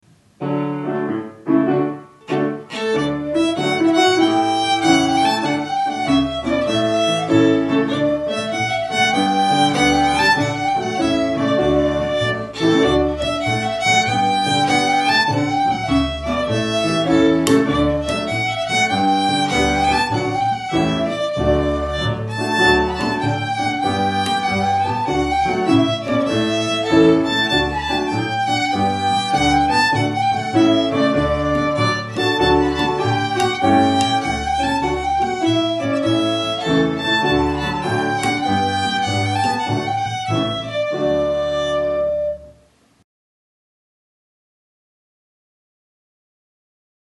Jig - D Major